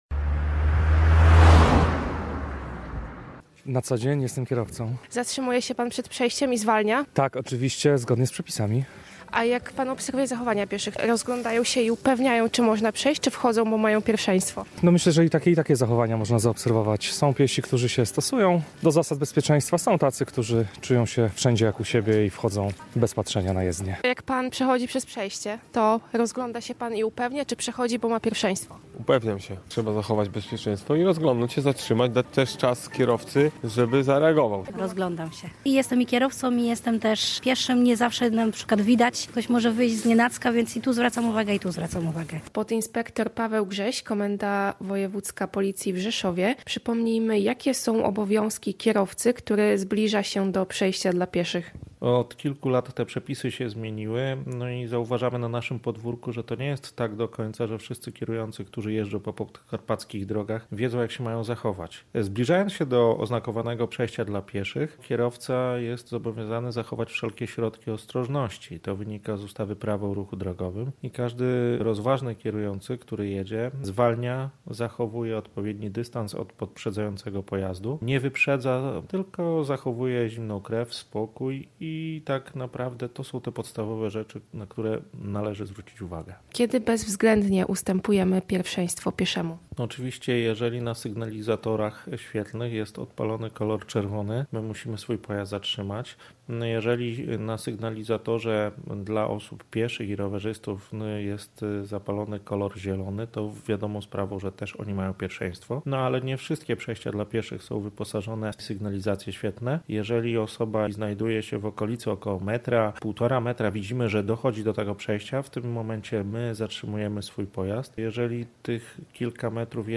Mniej wypadków z udziałem pieszych na podkarpackich przejściach • Relacje reporterskie • Polskie Radio Rzeszów